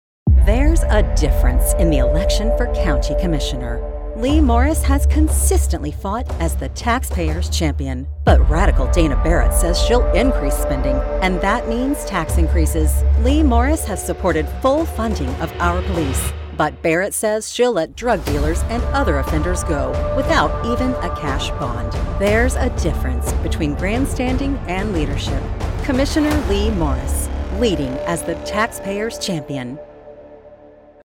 Female Republican Voices
Variety of great voice actors with pro home studios and Source Connect.